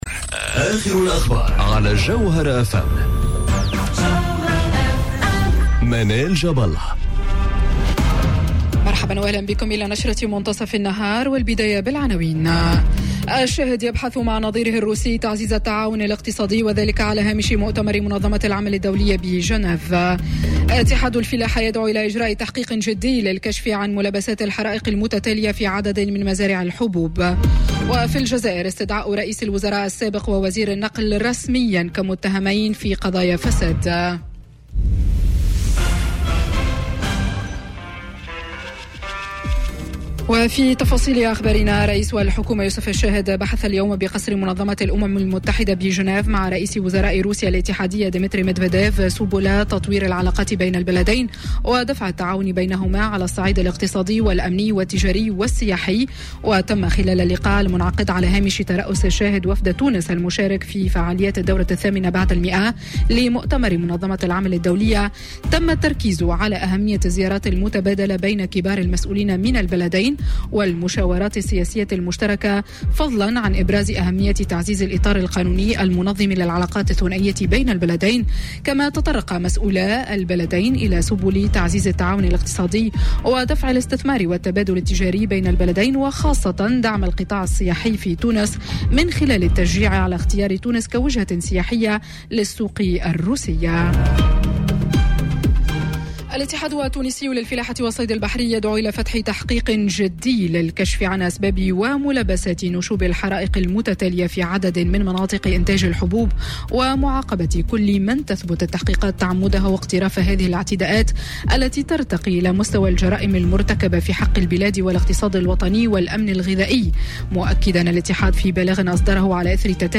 نشرة أخبار منتصف النهار ليوم الثلاثاء 11 جوان 2019